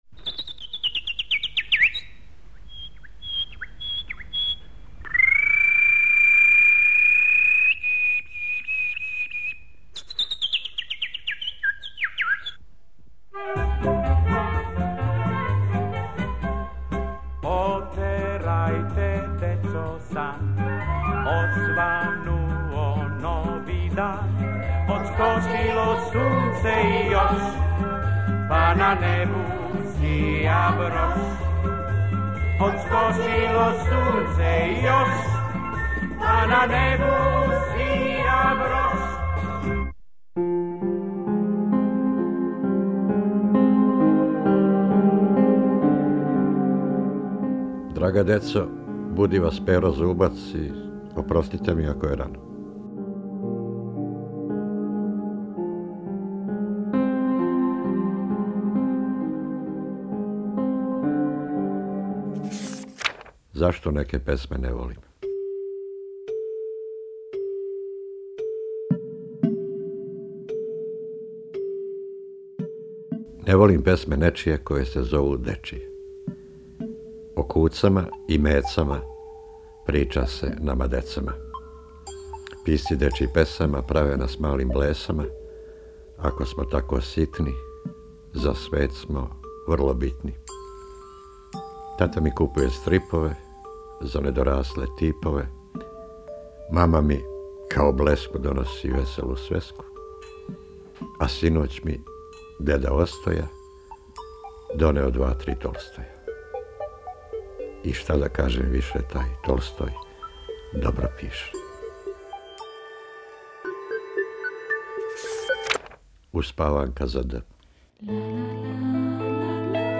Поезија
у пратњи Дечије драмске групе Радио Београда